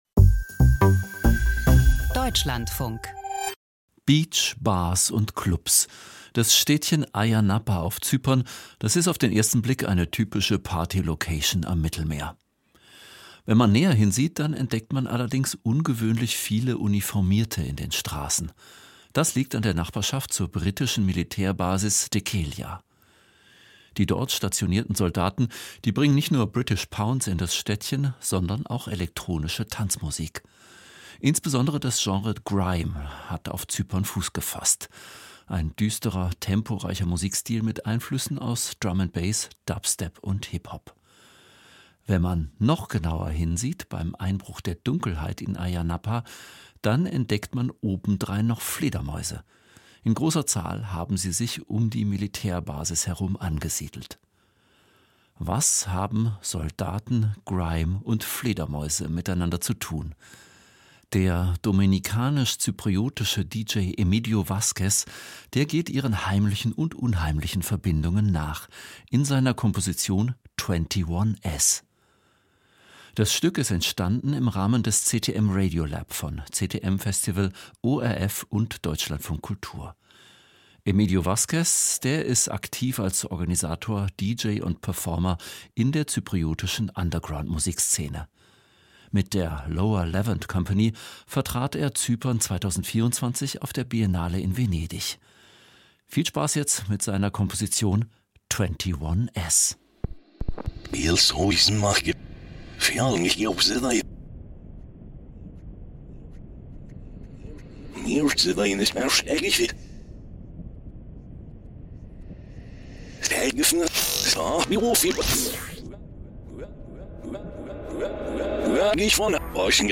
Klangkunst: Militär- und Clubkultur auf Zypern - 21s